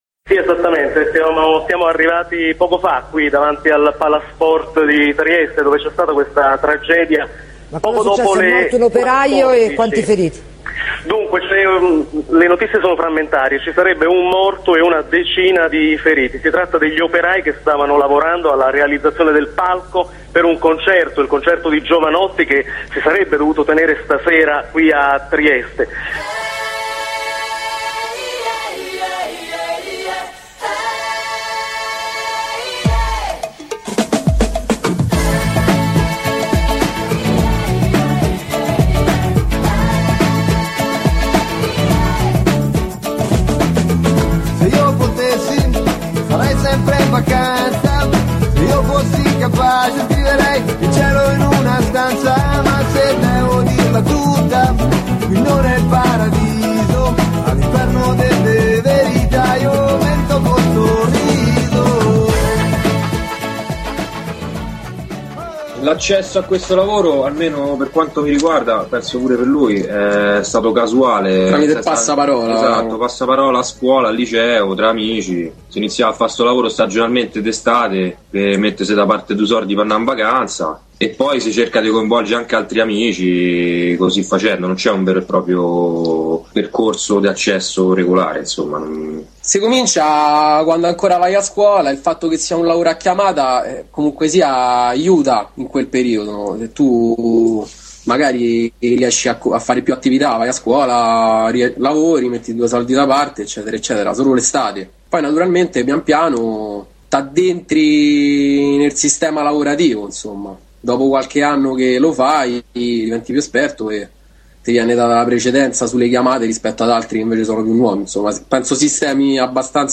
In questa piccola inchiesta alcuni lavoratori dello spettacolo di Roma entrano nel merito: come avviene la tosatura del salario per i facchini, come operano le cooperative di lavoro per operai semplici e specializzati, come si crea la concorrenza tra lavoratori, quali sono i reali livelli di sicurezza dopo l’introduzione del Tusl nel 2008 e dopo le recenti morti sui cantieri e infine alcune considerazioni sulle prese di posizione di alcuni artisti a riguardo.